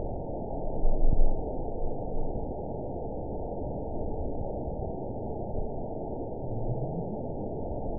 event 910554 date 01/22/22 time 03:47:13 GMT (3 years, 10 months ago) score 9.45 location TSS-AB02 detected by nrw target species NRW annotations +NRW Spectrogram: Frequency (kHz) vs. Time (s) audio not available .wav